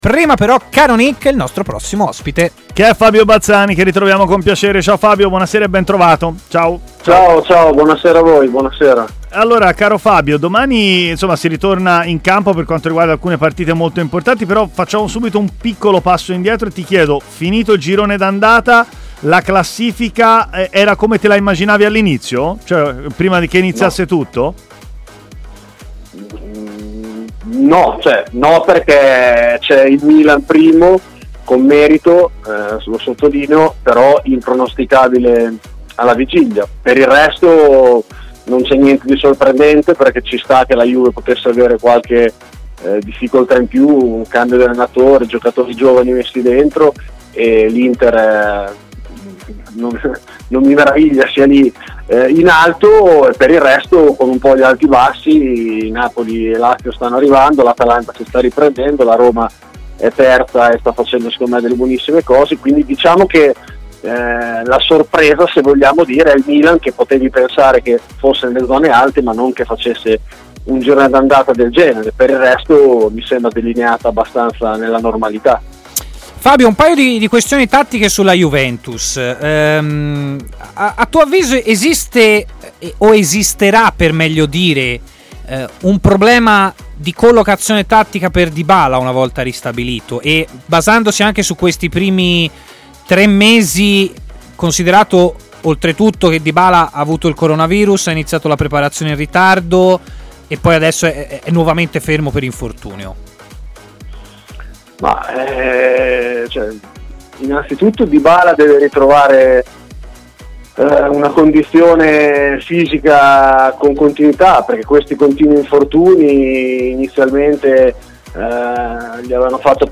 in diretta a TMW Radio, nel corso della trasmissione Stadio Aperto